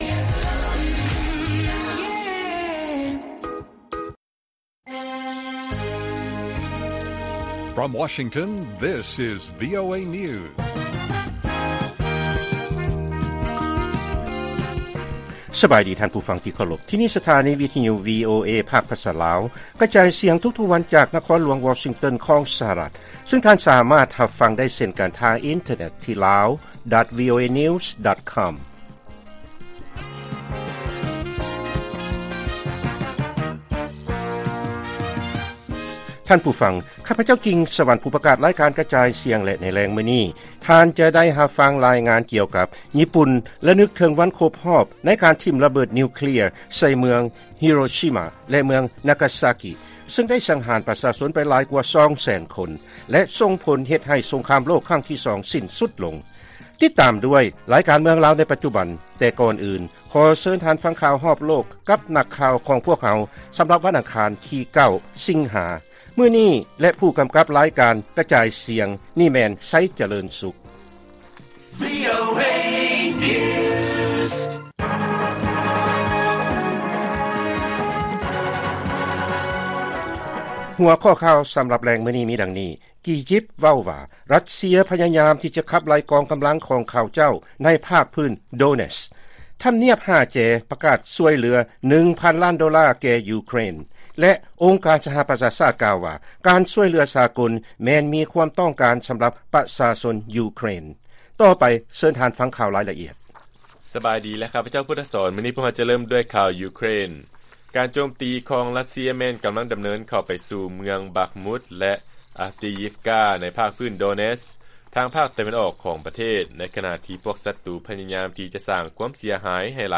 ລາຍການກະຈາຍສຽງຂອງວີໂອເອ ລາວ: ກີຢິບ ເວົ້າວ່າ ຣັດເຊຍ ພະຍາຍາມທີ່ຈະຂັບໄລ່ກອງກຳລັງຂອງເຂົາເຈົ້າໃນພາກພື້ນ ໂດເນັດສ໌